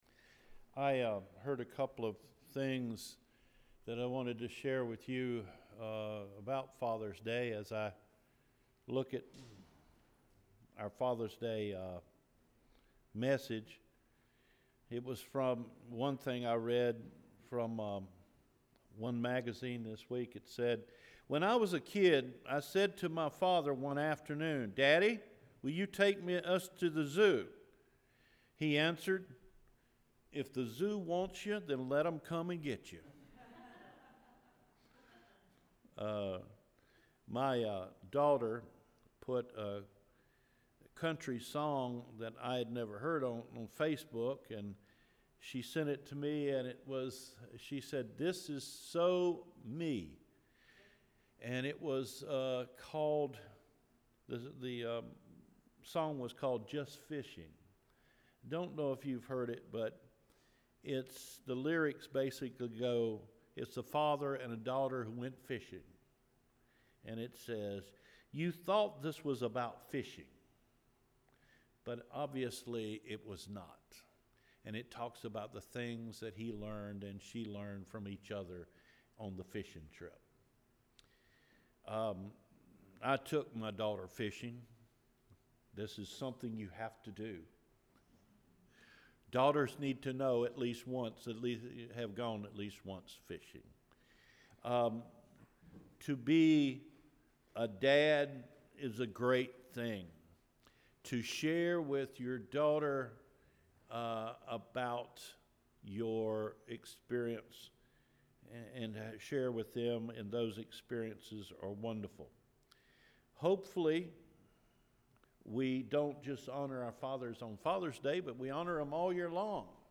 Father’s Day – June 18 Sermon